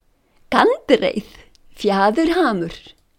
Listen to pronunciation: gandreið fjaðurhamur,